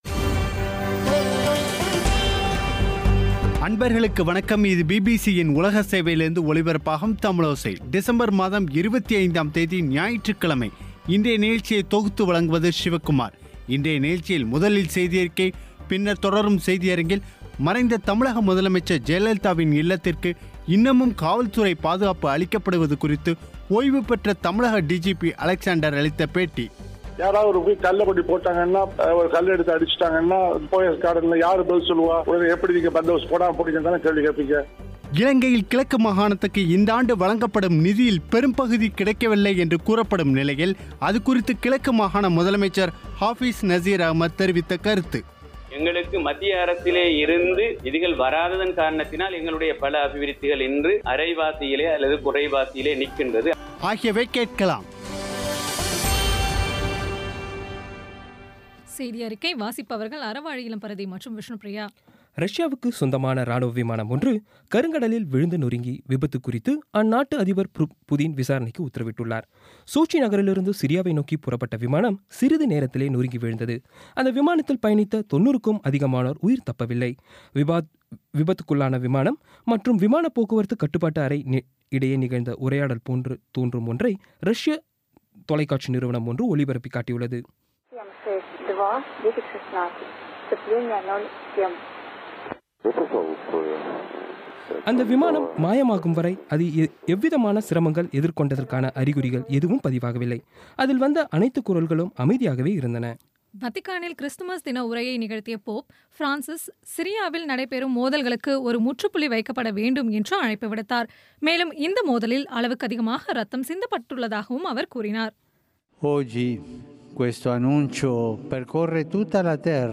இன்றைய நிகழ்ச்சியில் முதலில் செய்தியறிக்கை, பின்னர் தொடரும் செய்தியரங்கில்
மறைந்த தமிழக முதலமைச்சர் ஜெயலலிதாவின் இல்லத்திற்கு இன்னமும் காவல்துறை பாதுகாப்பு அளிக்கப்படுவது குறித்து ஓய்வுபெற்ற தமிழக டிஜிபி அலெக்ஸான்டர் அளித்த பேட்டி